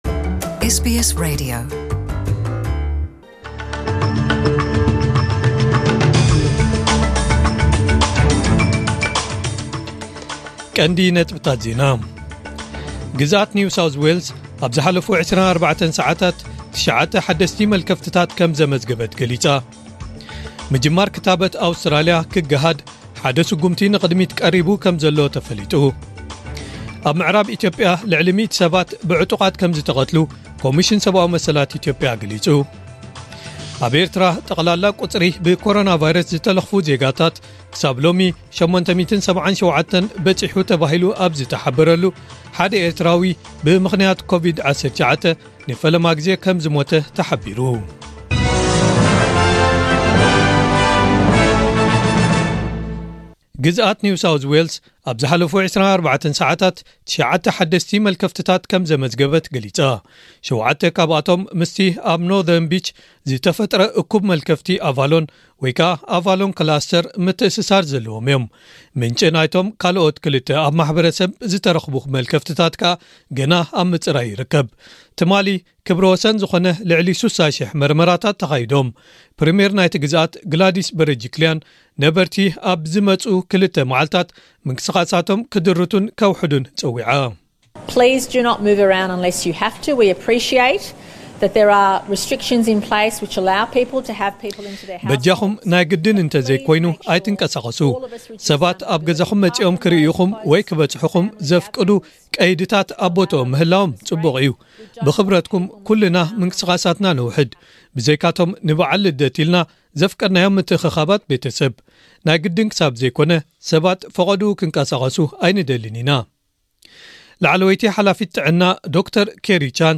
ኤስቢኤስ ዕለታዊ ዜና